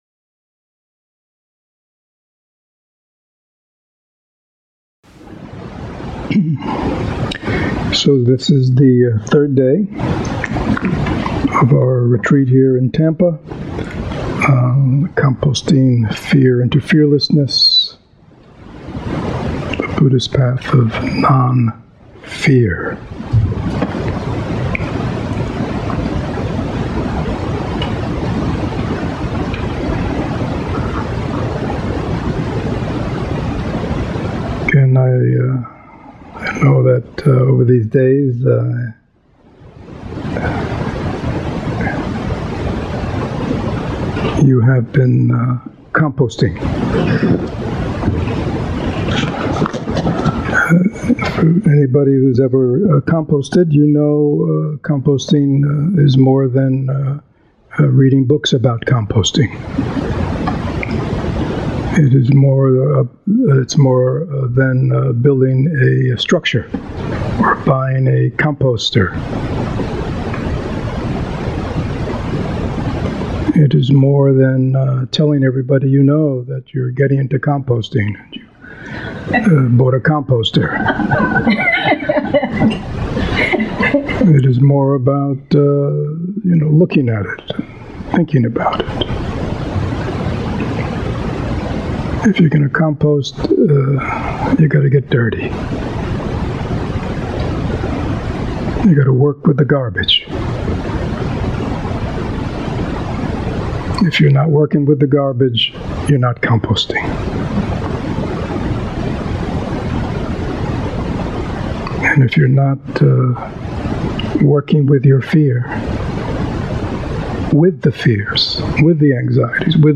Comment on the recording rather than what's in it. Three-day Retreat